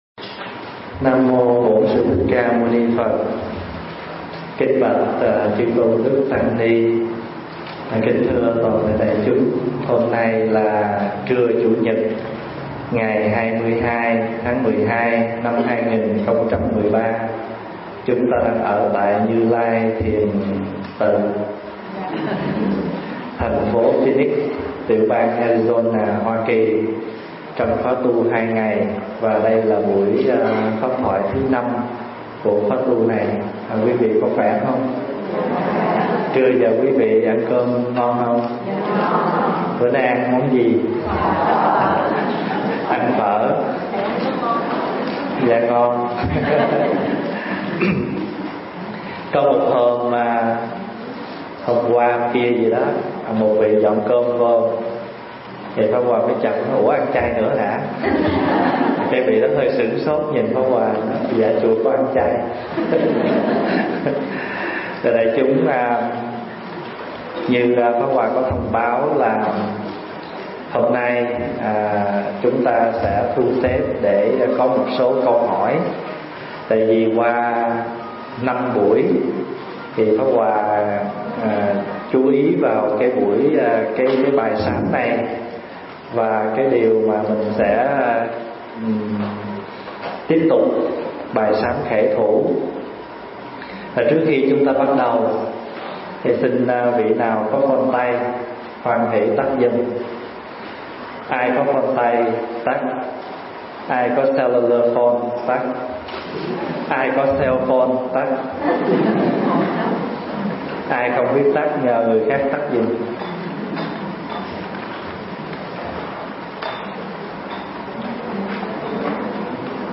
thuyết giảng tại Như Lai Thiền Tự